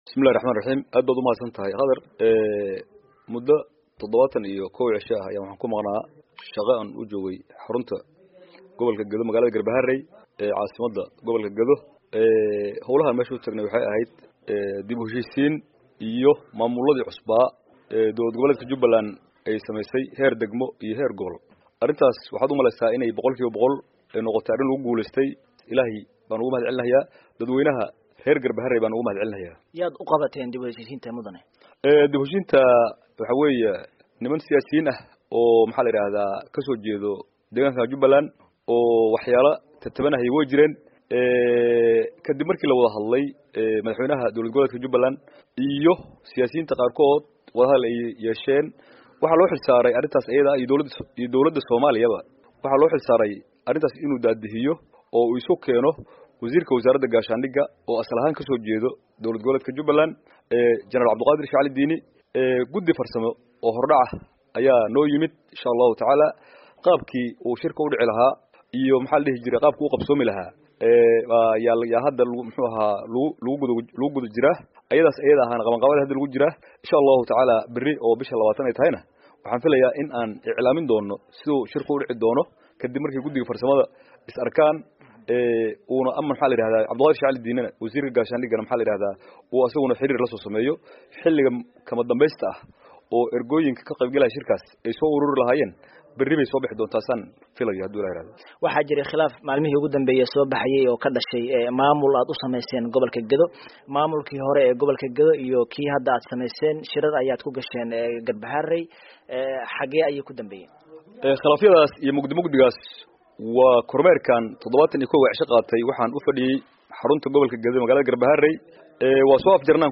Wareysi: Madaxweyne Ku Xigeenka Jubbaland